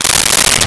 Stapler Open 01
Stapler Open 01.wav